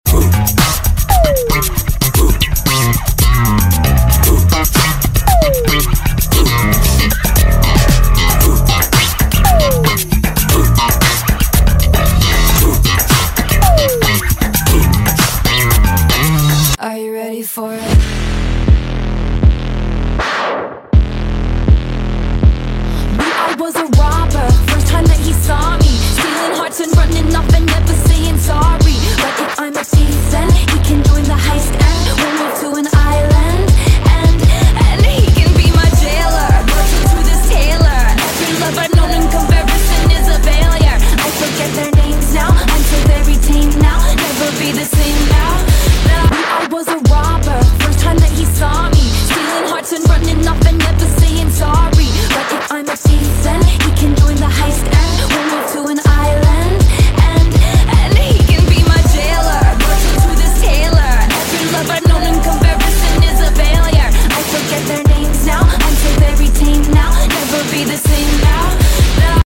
122funk.mp3